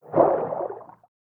Fish_Attack2.ogg